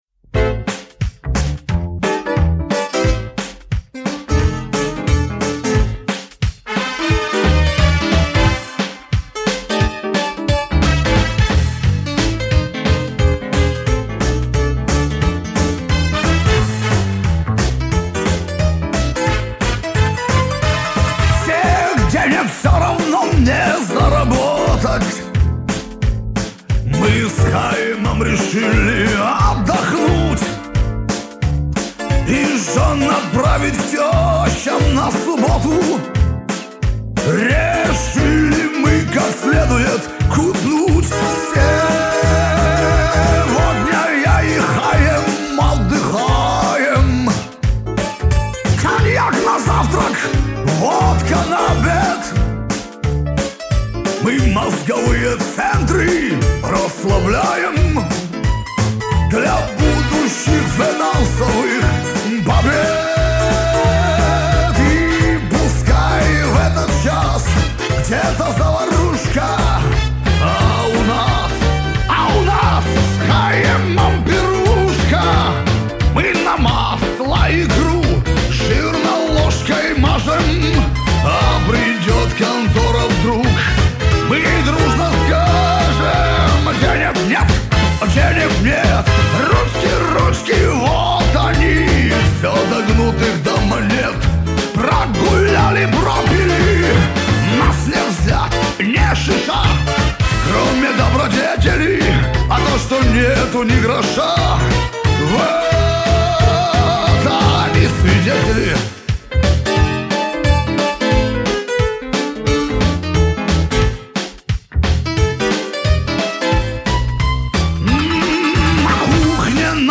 шансоном